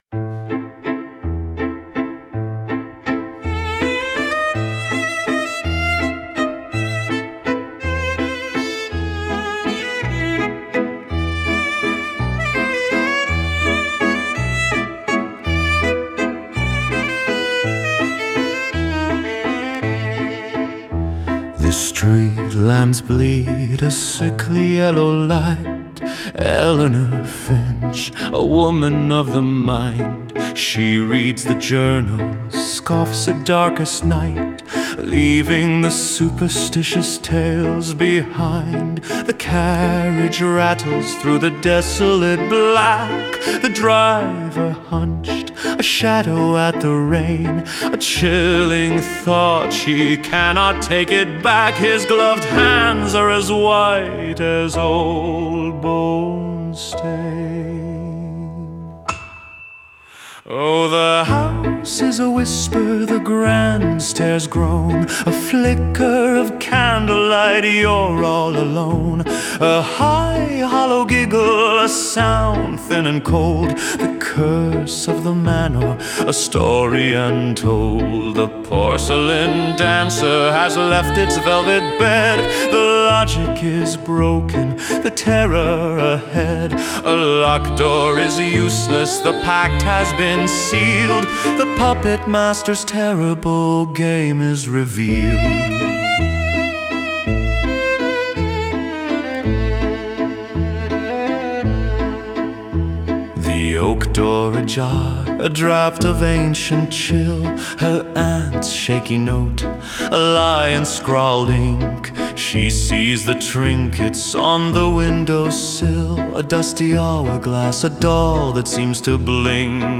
tempo_-slow-with-a-heavy-driving-beat.mp3